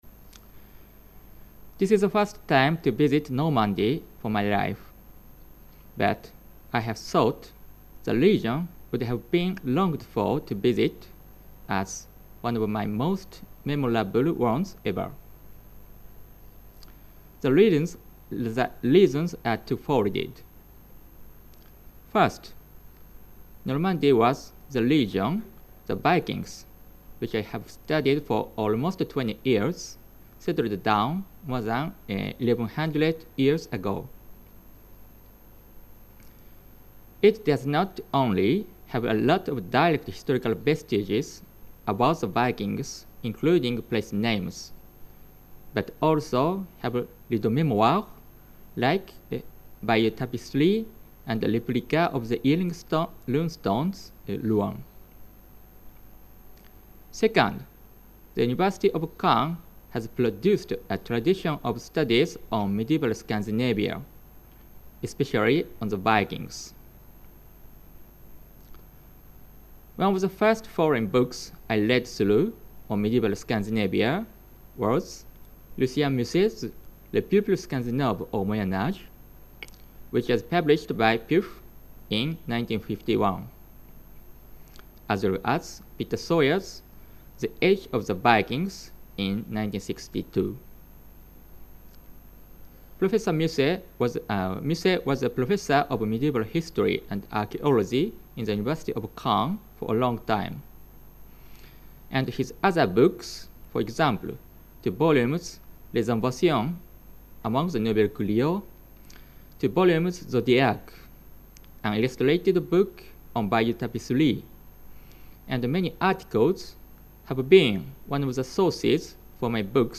Cette communication a été filmée dans le cadre du séminaire du CRAHAM consacré aux écritures vernaculaires dans les mondes germaniques et scandinaves.